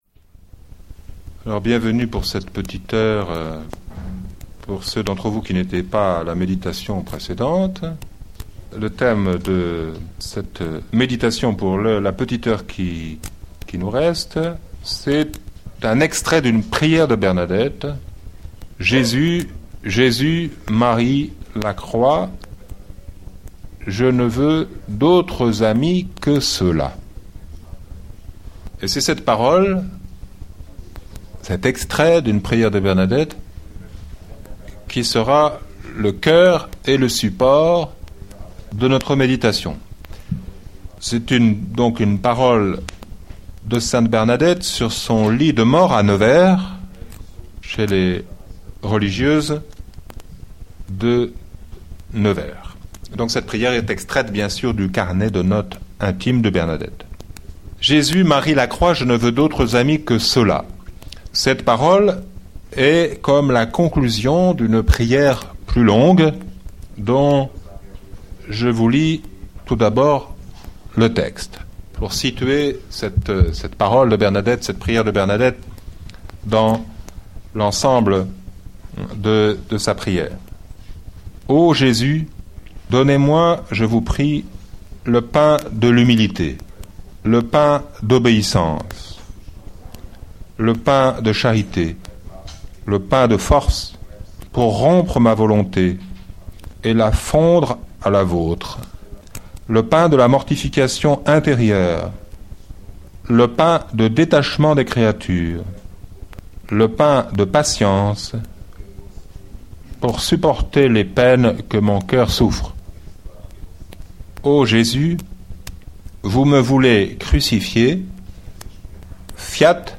Carrefour.